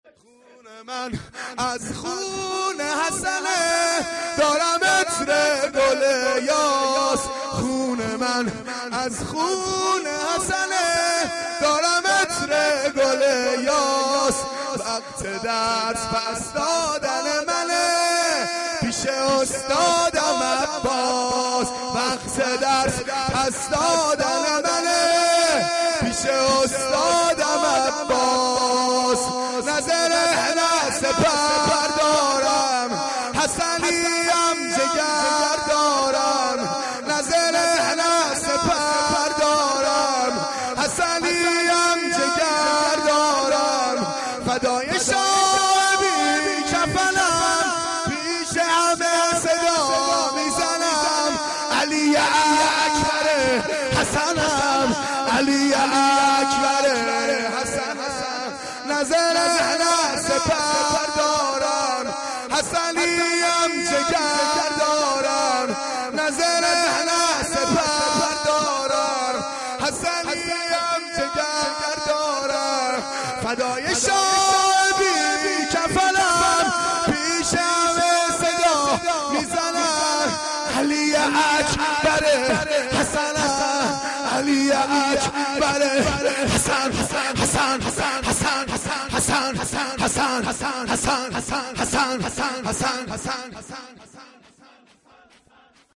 شور شب ششم